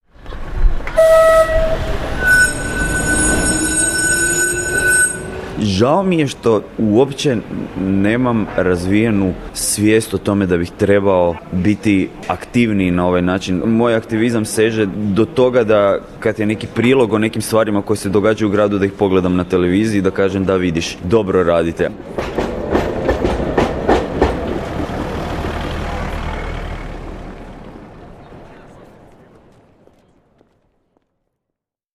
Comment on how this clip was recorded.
STATEMENTS BROADCASTED ON RADIO "SLJEME":